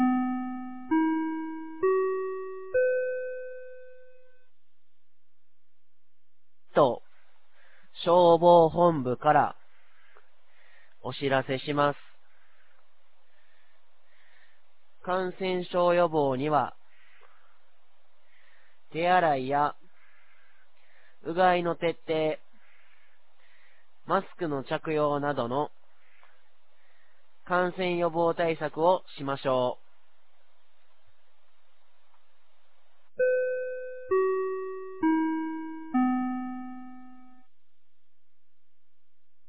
2023年02月06日 10時00分に、九度山町より全地区へ放送がありました。